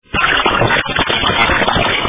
саундтрек из рекламы